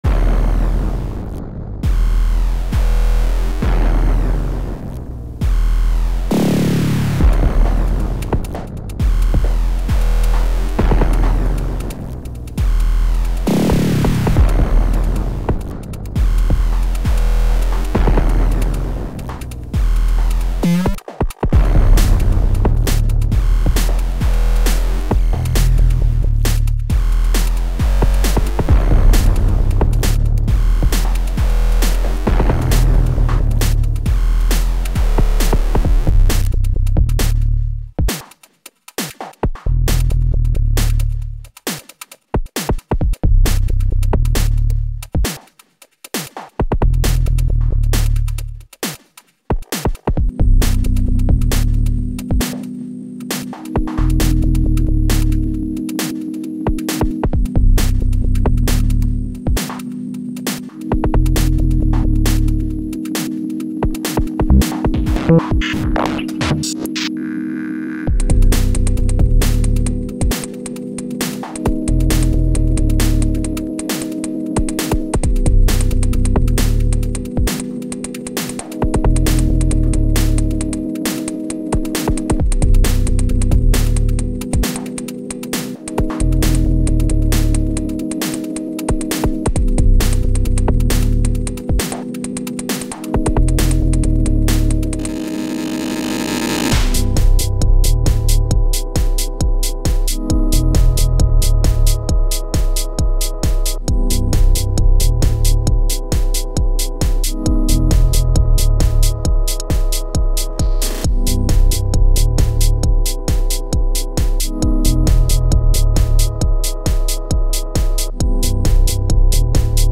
Techno/garage